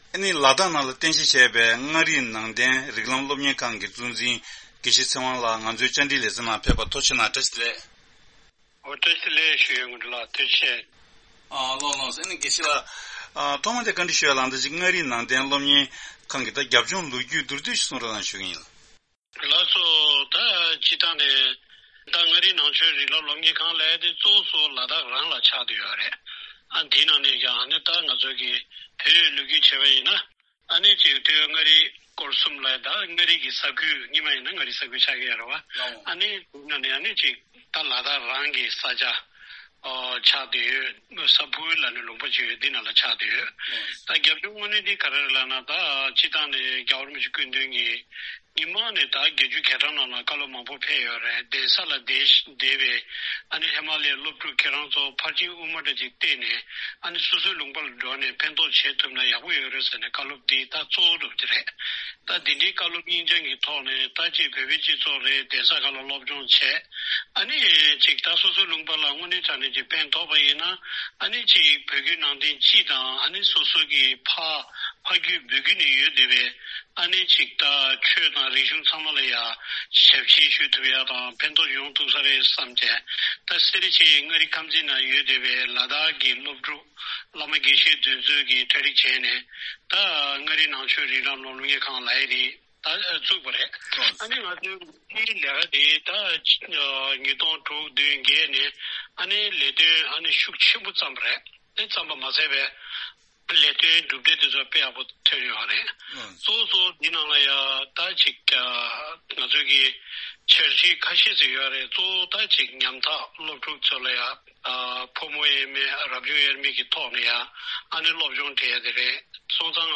གནས་འདྲི་ཞུས་པ་ཞིག་